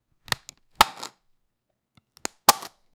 open-surprise-box-afilnqo5.wav